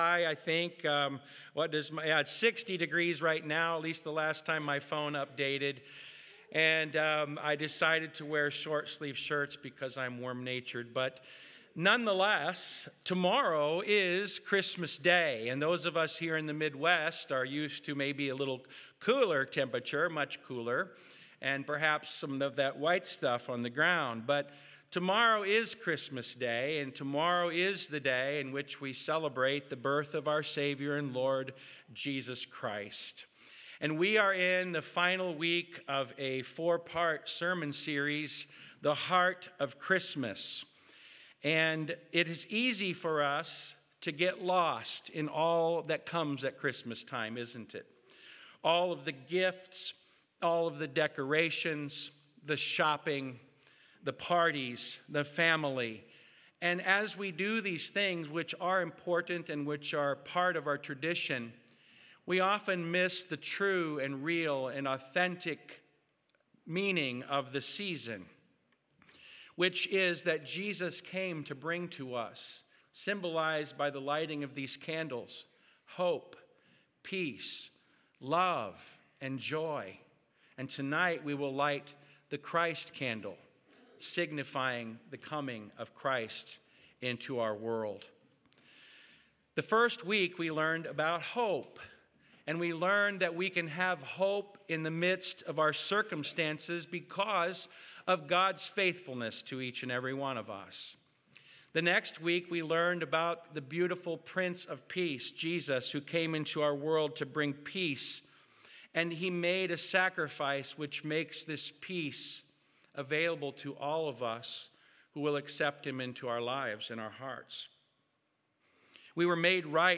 Sermon: The Heart of Christmas Brings Joy in All Circumstances